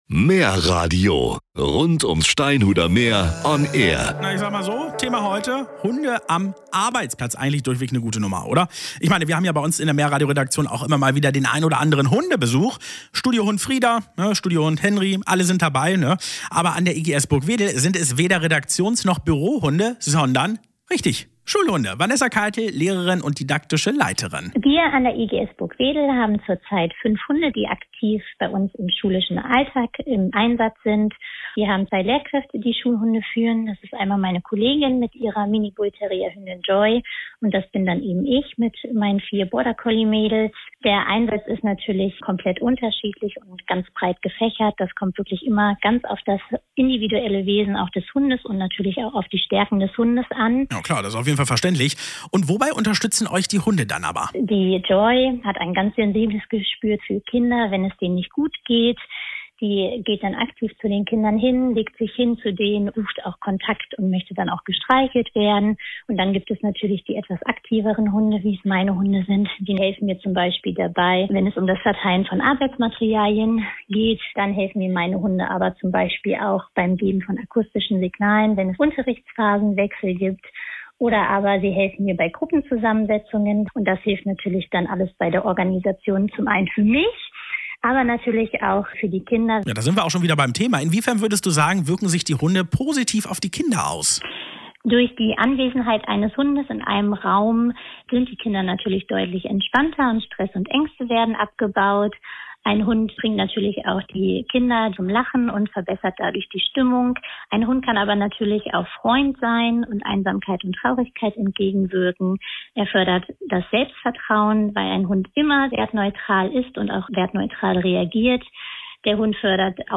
Herausgekommen ist ein ganz tolles Interview, welches ihr nicht verpassen solltet.